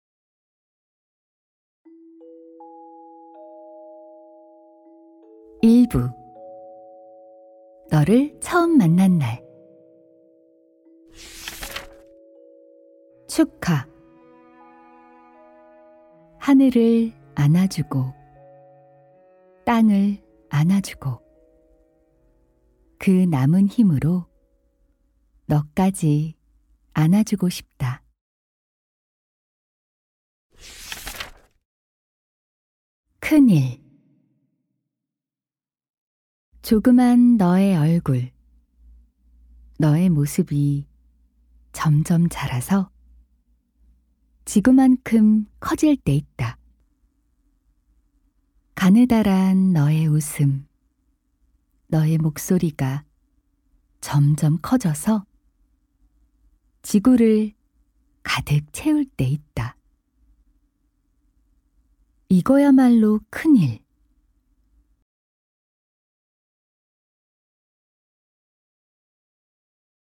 따스하고 편안한 목소리로 낭독하는